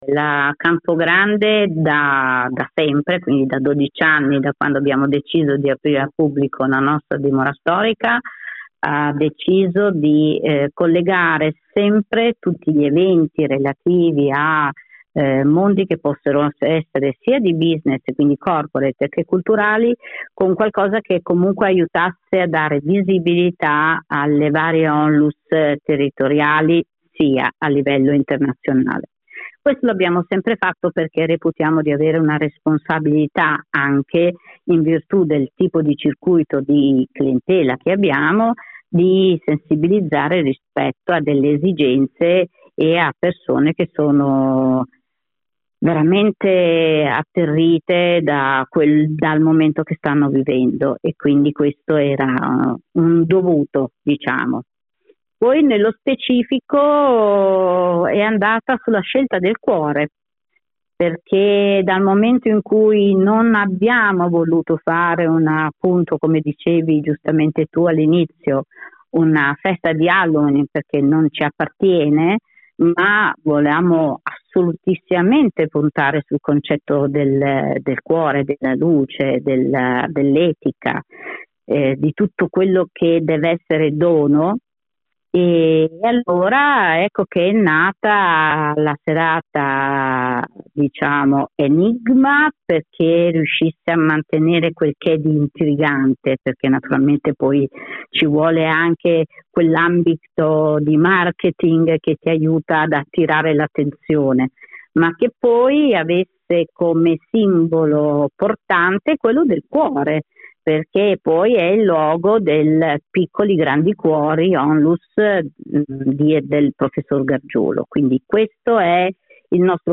Home Magazine Interviste A Crespellano (BO) arriva “Enigma.